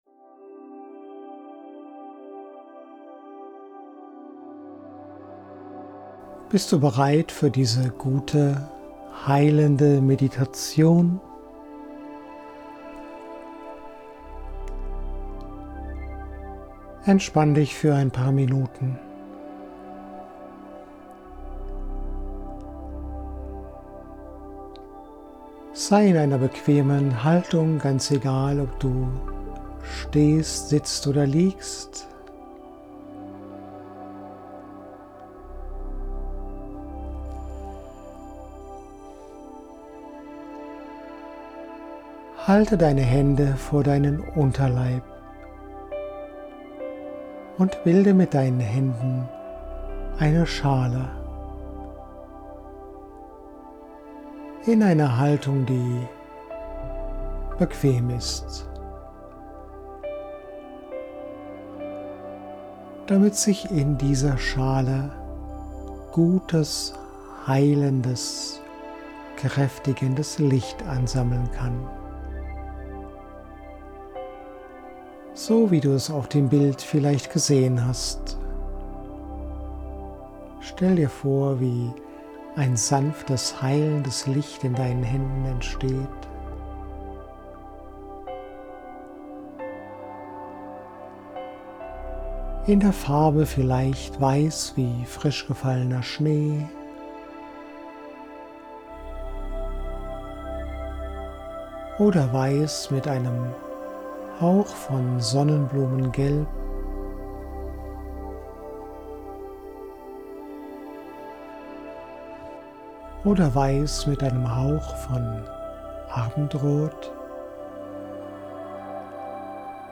Heilungs-Meditation: Visualisierung heilendes Licht
Es ist eine reine Tonaufnahme.
Visualisierung_Heildendes_Licht.mp3